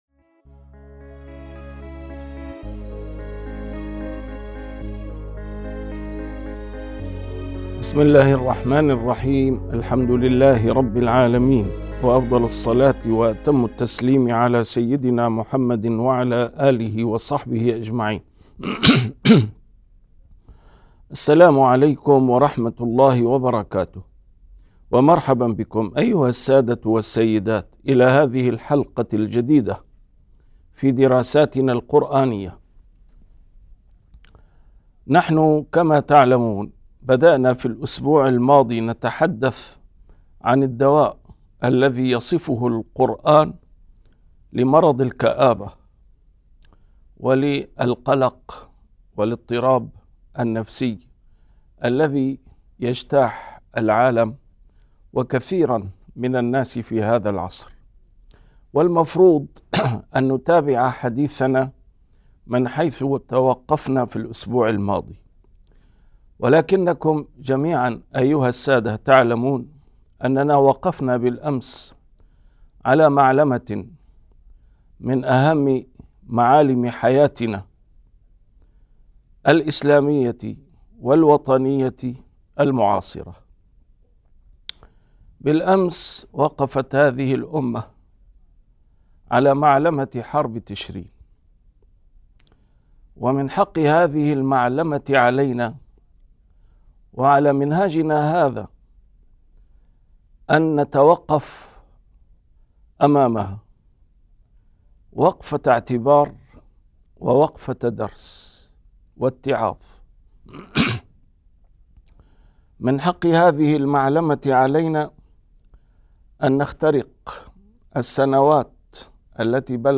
A MARTYR SCHOLAR: IMAM MUHAMMAD SAEED RAMADAN AL-BOUTI - الدروس العلمية - محاضرات متفرقة في مناسبات مختلفة - ما الذي جعل من معركة تشرين حرباً مصيرية ؟